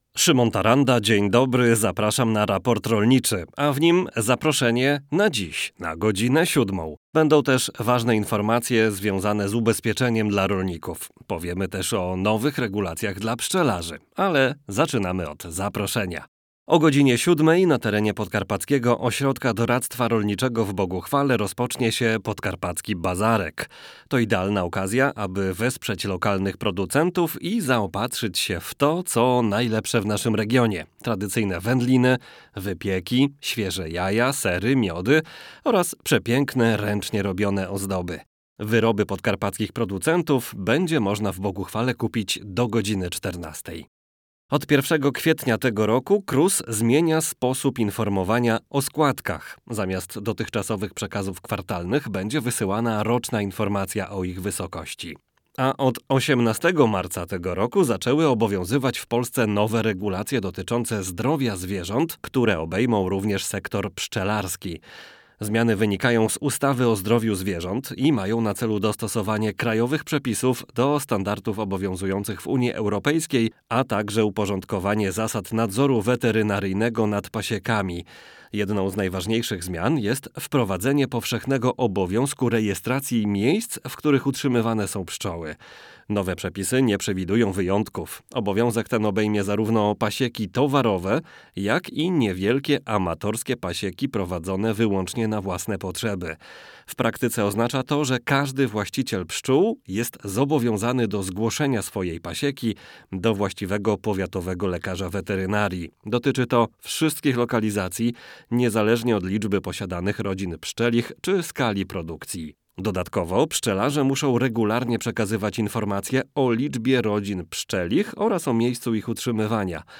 Zapraszamy do wysłuchania całego odcinka, a w nim: aktualne informacje oraz rozmowa z gościem